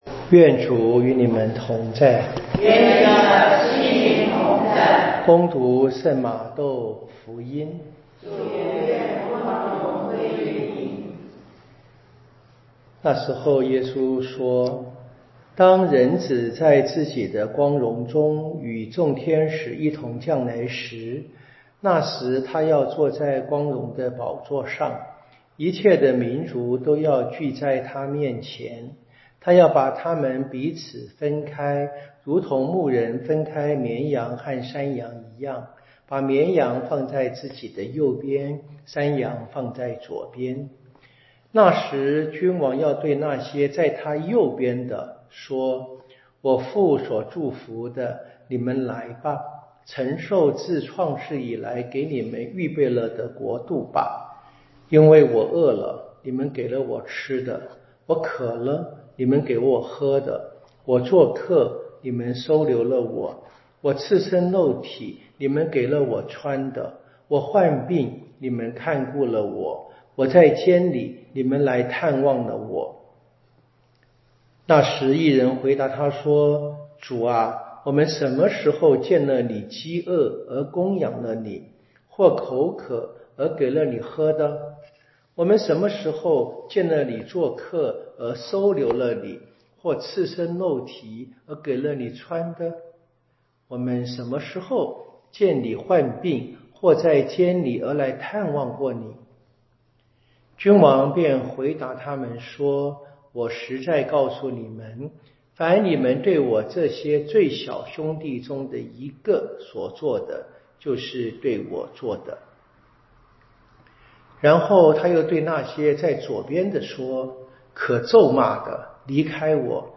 彌撒講道與聖經課程